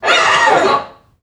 NPC_Creatures_Vocalisations_Robothead [5].wav